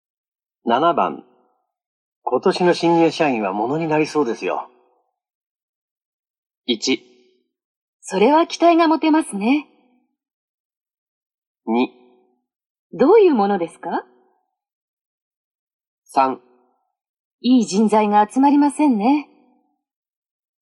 类别: N1听力库